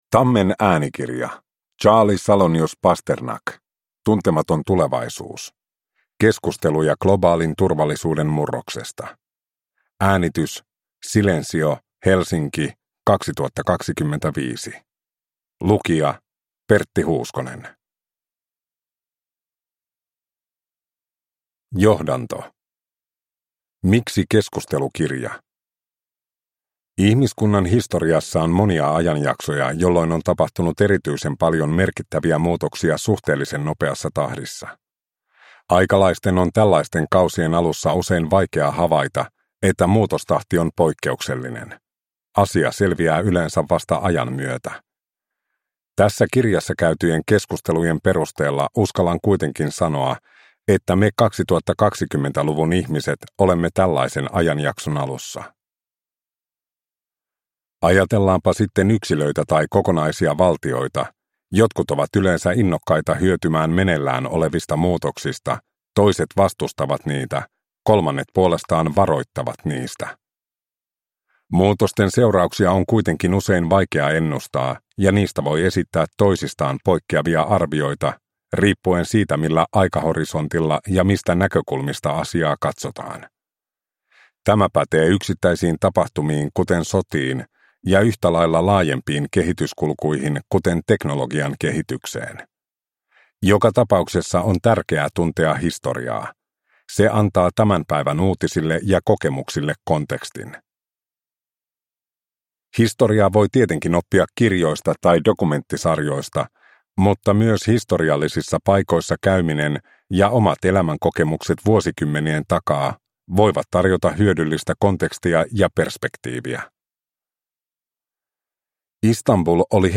Tuntematon tulevaisuus – Ljudbok